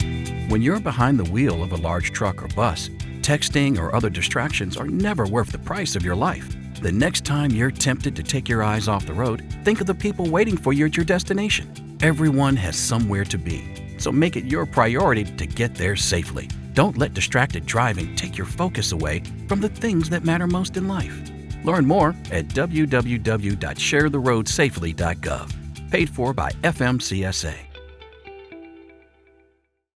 FMCSA_Distracted Driving Radio .30 seconds.wav